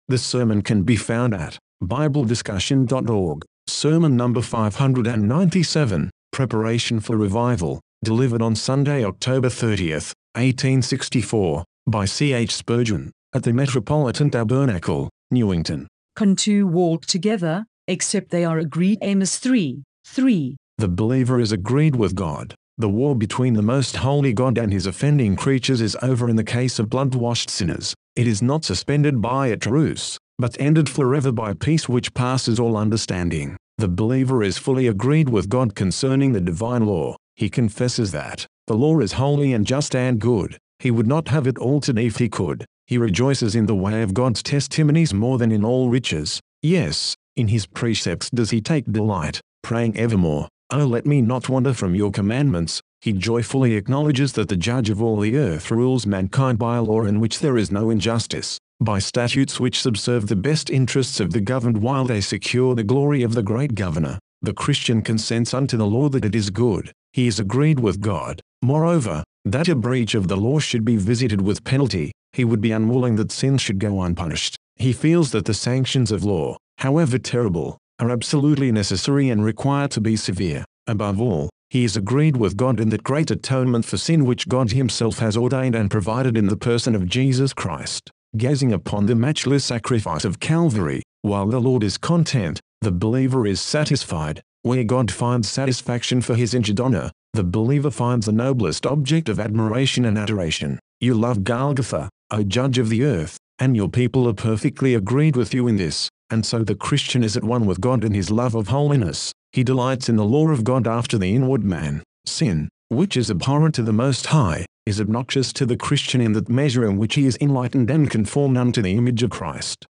Sermon #598 – Two Visions
Delivered On Sunday Morning, November 6, 1864, By C. H. Spurgeon, At The Metropolitan Tabernacle, Newington.